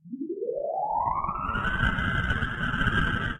磁带异常 " TAP E35
描述：最初是1971年用廉价设备在嘈杂的环境中录制的。
Tag: 足球 卷到卷轴 磁带